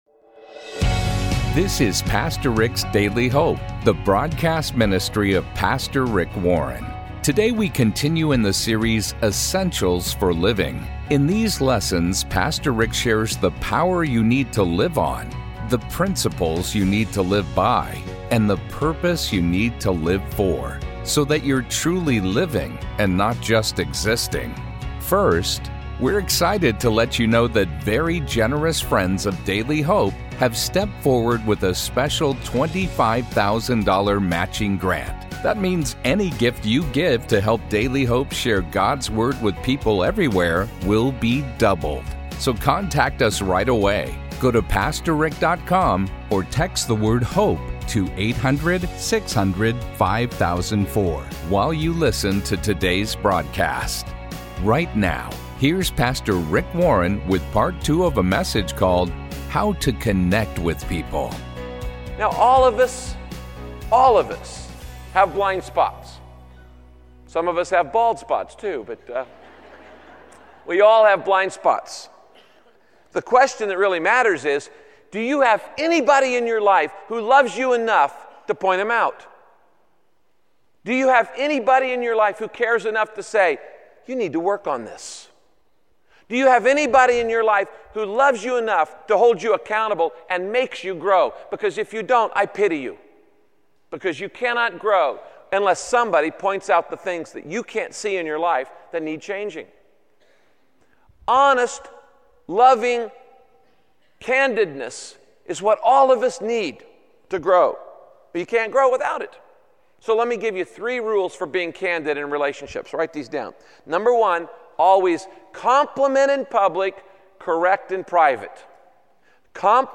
My Sentiment & Notes How to Connect with People - Part 2 Podcast: Pastor Rick's Daily Hope Published On: Mon Aug 21 2023 Description: In this message, Pastor Rick shares biblical teachings on how gossip can harm friendships, families, and churches. He also offers guidance on how to respond when you’ve been hurt by someone gossiping about you.